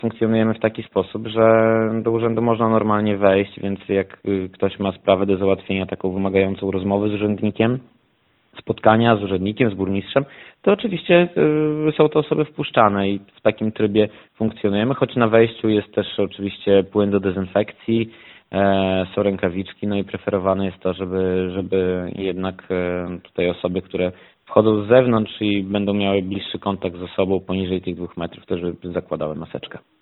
– Przed wejściem do urzędu należy pamiętać o dezynfekcji rąk, włożeniu maseczki oraz zachowaniu dystansu między osobami – mówi Filip Chodkiewicz, zastępca burmistrza Augustowa.